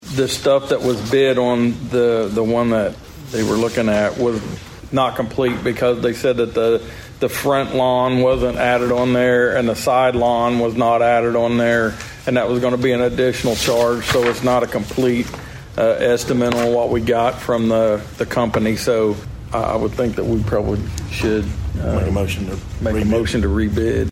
At Monday's Osage County Commissioners meeting, there was hope the Board would have a lawn maintenance provider to take care of the courthouse, annex building and Old Matthew's House.